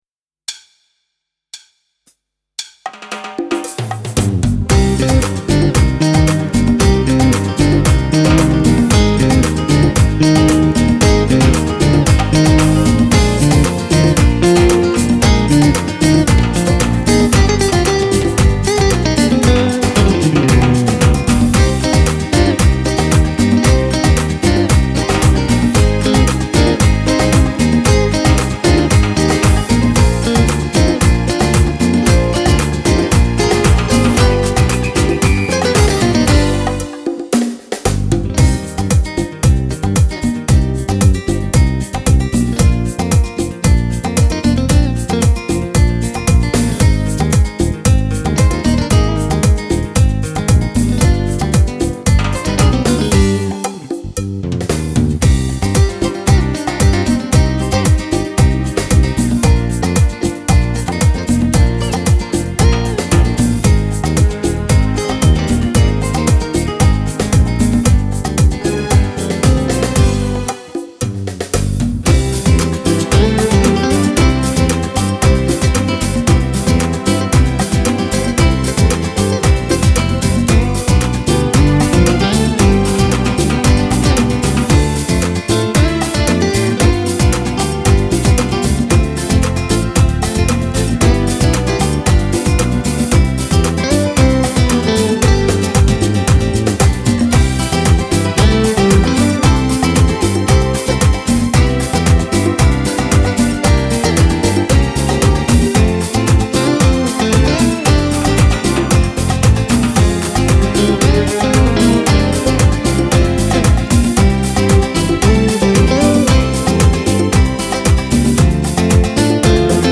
Latin gipsy
Fisarmonica